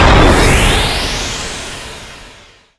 use_superjump_alternate.wav